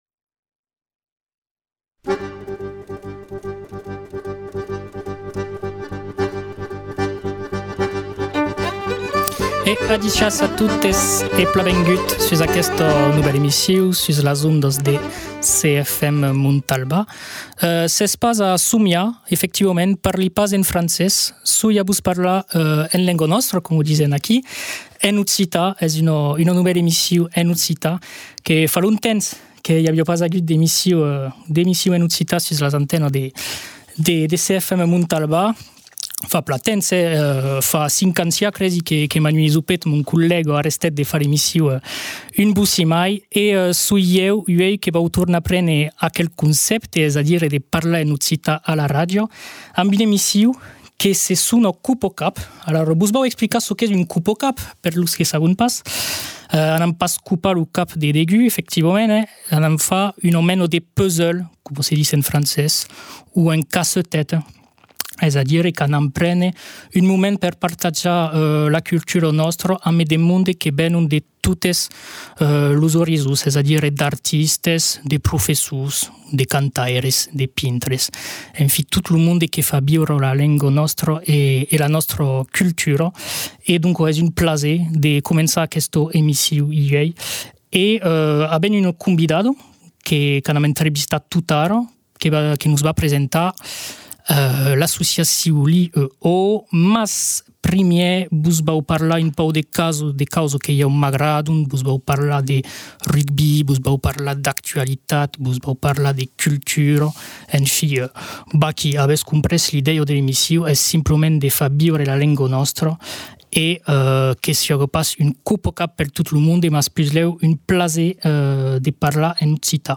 Émissions